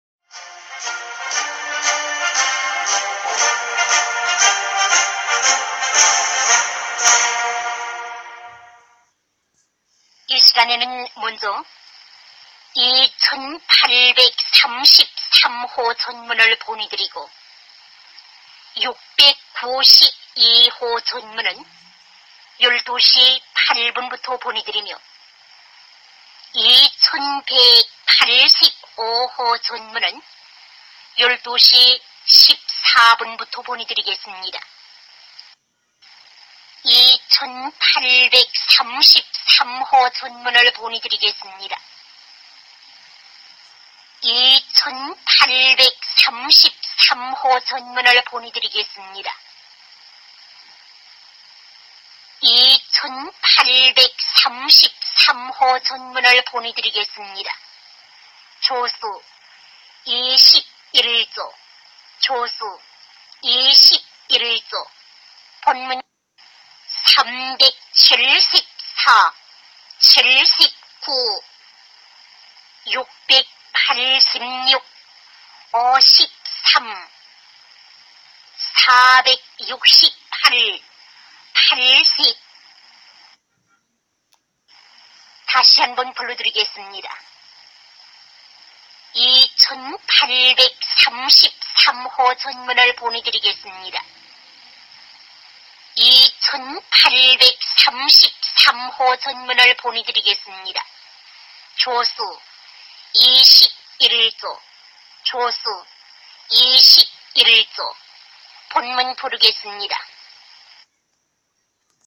朝鲜电台.mp3